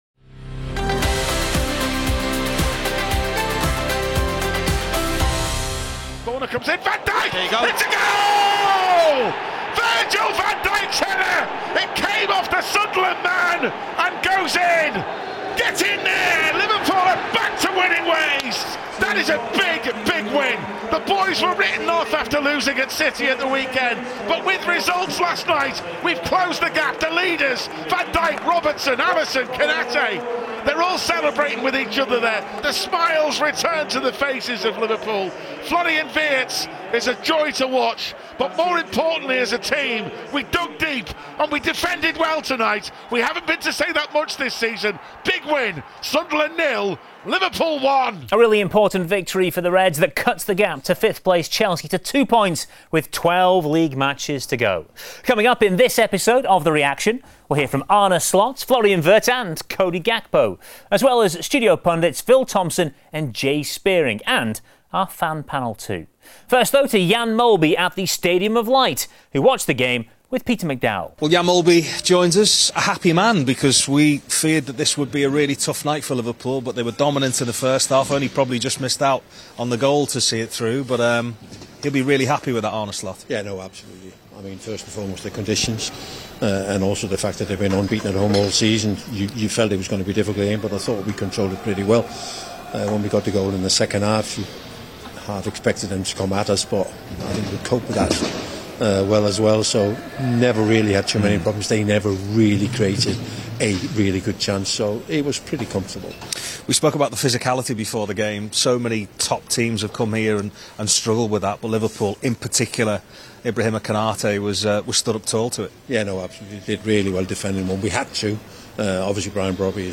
Arne Slot, Florian Wirtz and Cody Gakpo reflect on Liverpool’s 1-0 win at Sunderland after Virgil van Dijk’s header cut the gap to fifth-placed Chelsea to two points.